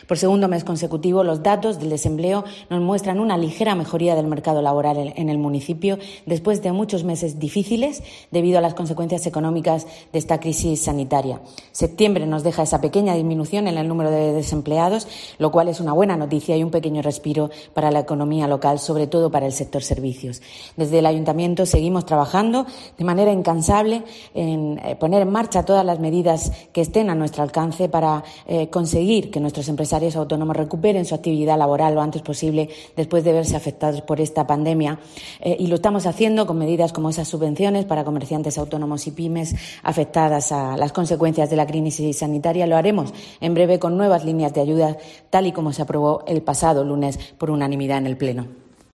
Isabel Casalduero, edil del Ayuntamiento de Lorca sobre paro